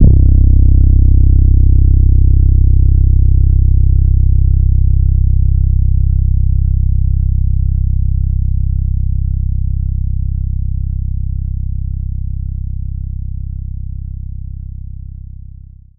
BWB WAV 7 808 (4).wav